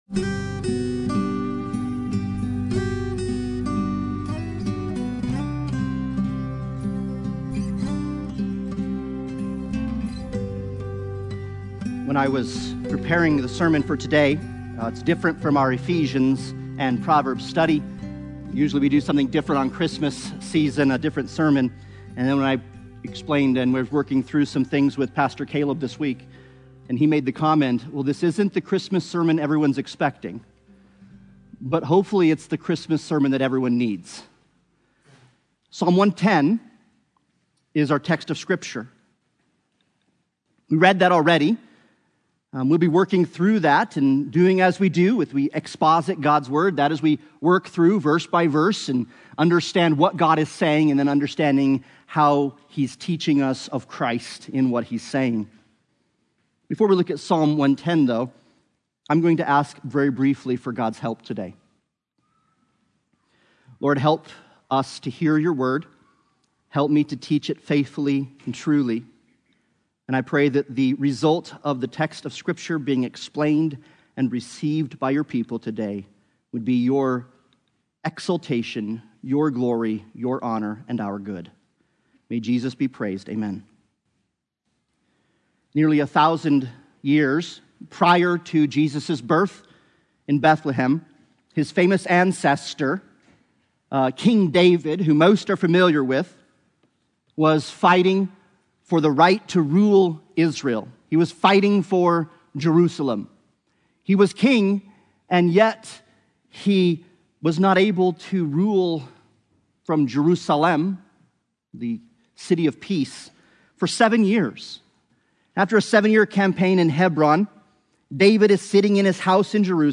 Psalm 110 Service Type: Sunday Morning Worship « The Gospel According to Mark Proverbs 6:10-35